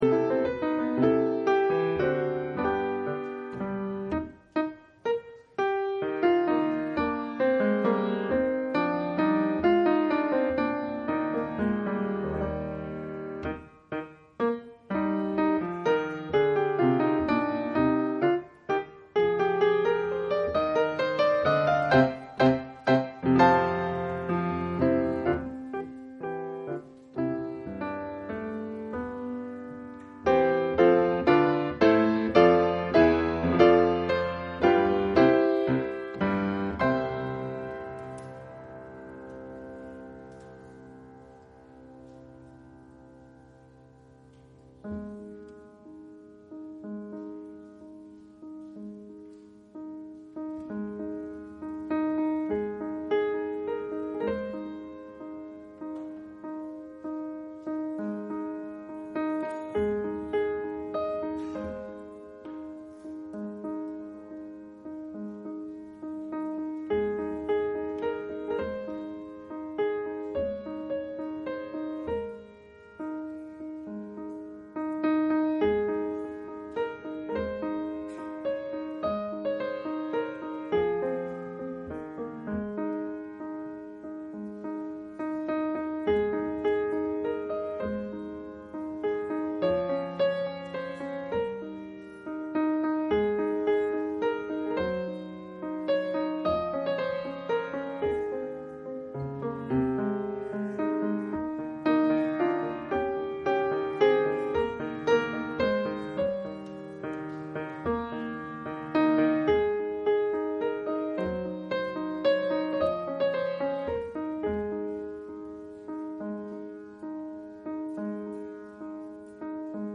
Public Reading of Holy Scripture
Service Type: Sunday Afternoon